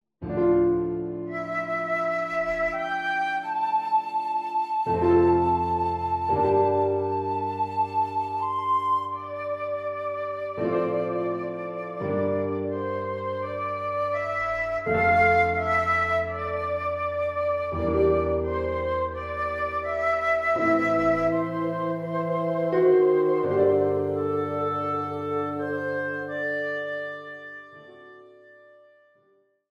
A short extract from the opening:
Piano, Flute, Oboe, Clarinet & Bassoon